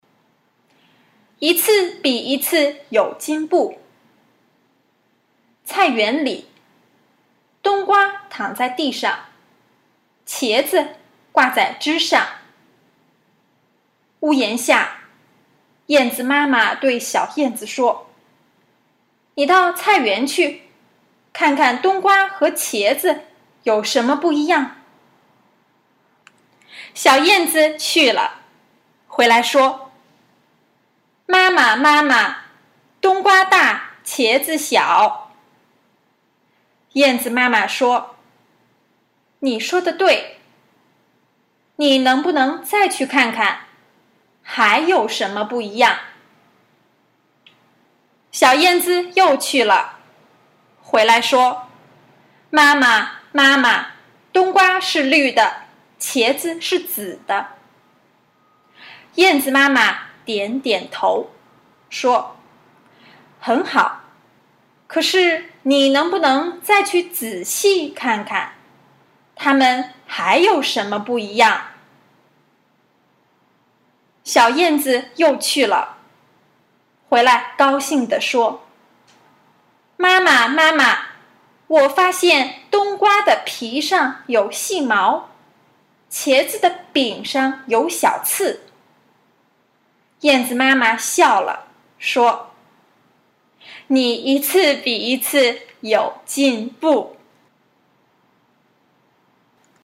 Luyện nghe tiếng Trung qua truyện ngắn Càng ngày càng tiến bộ